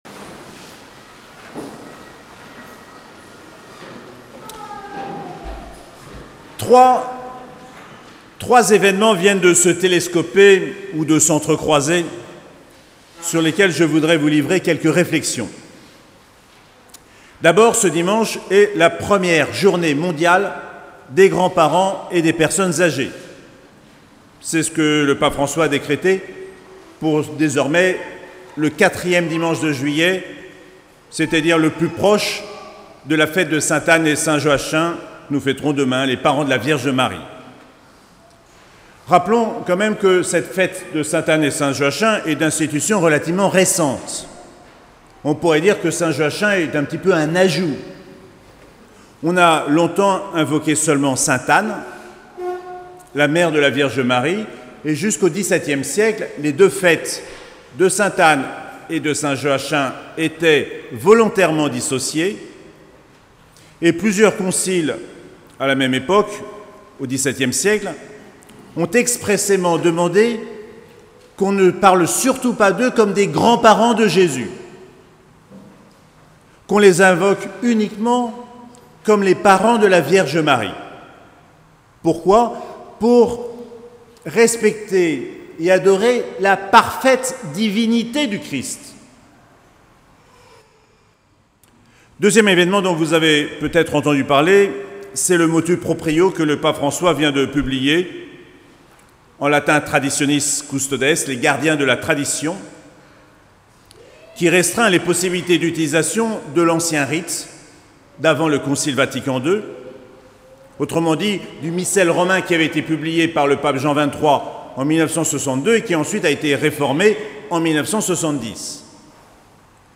17ème Dimanche du Temps Ordinaire - 25 juillet 2021